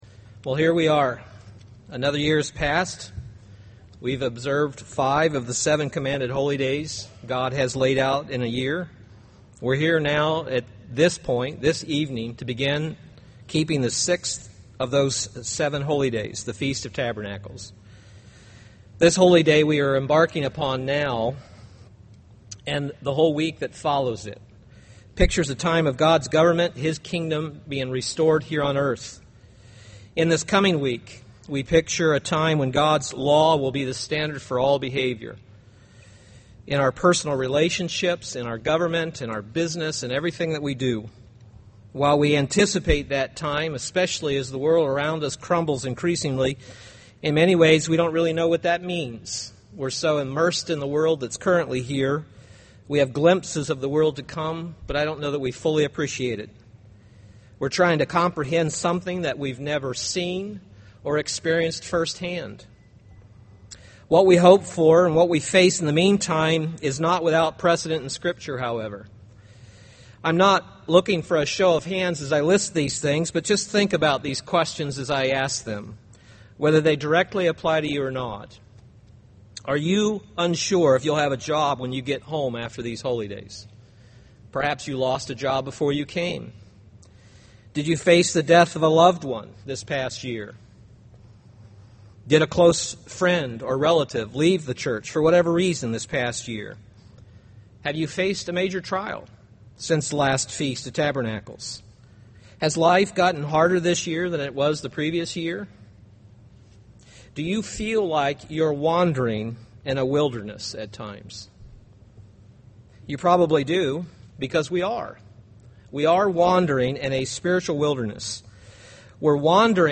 This sermon was given at the Wisconsin Dells, Wisconsin 2011 Feast site.